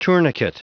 Prononciation du mot tourniquet en anglais (fichier audio)
Prononciation du mot : tourniquet